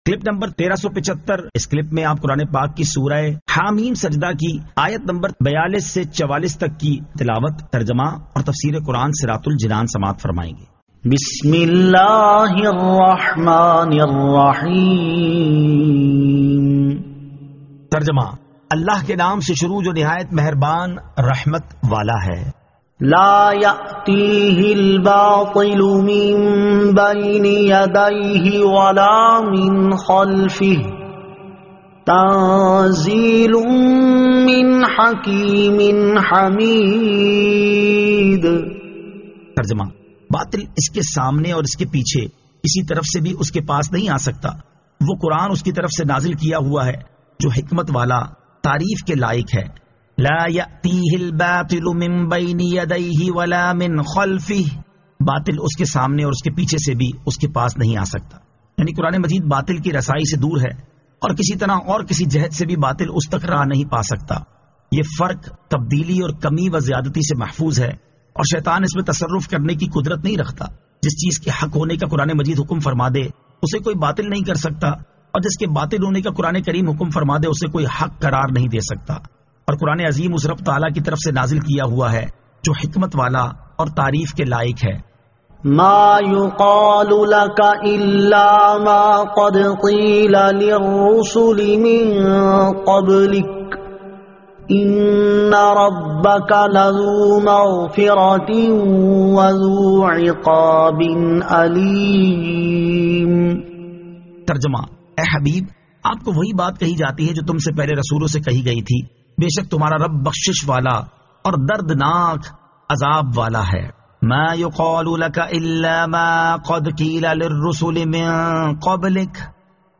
Surah Ha-Meem As-Sajdah 42 To 44 Tilawat , Tarjama , Tafseer